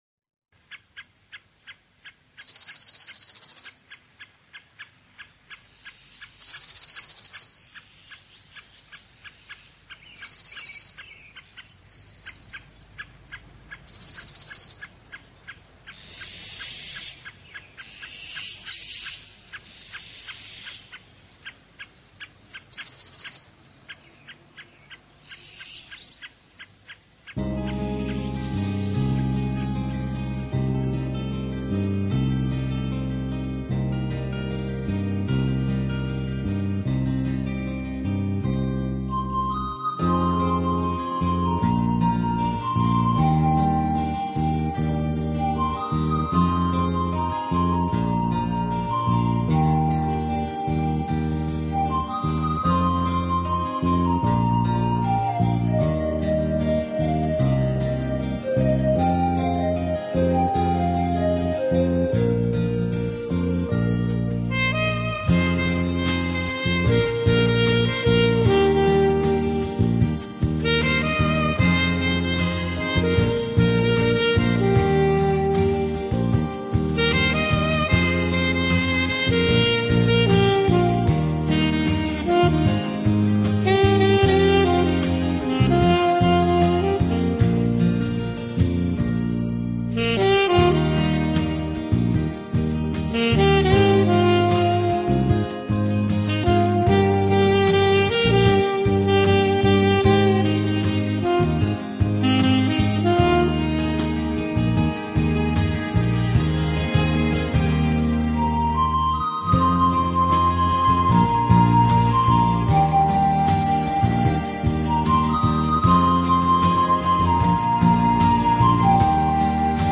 合成乐音为演奏的主体，再结合他们所采撷的大自然音效，在这两相结合之下，您可
器配置，使每首曲子都呈现出清新的自然气息。